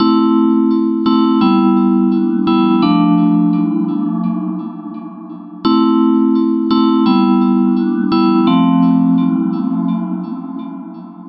描述：氛围, 寒意, 嘻哈
Tag: 85 bpm Hip Hop Loops Bells Loops 972.84 KB wav Key : A